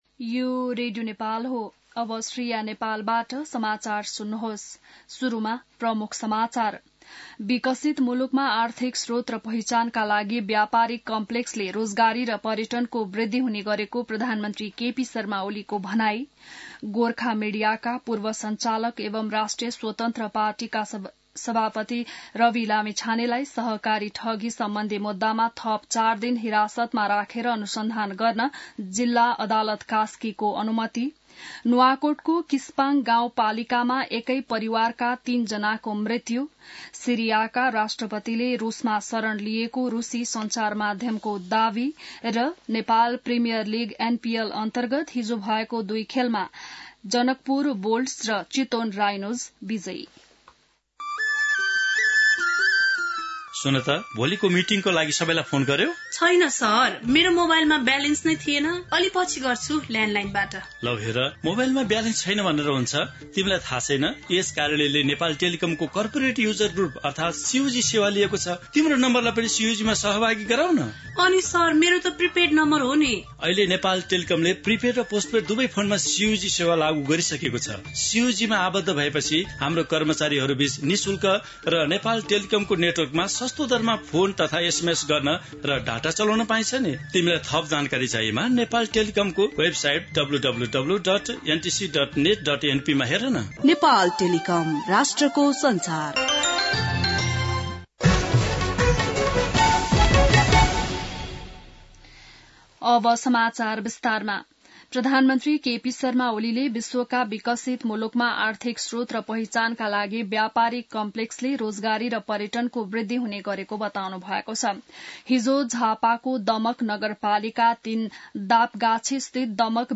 बिहान ७ बजेको नेपाली समाचार : २५ मंसिर , २०८१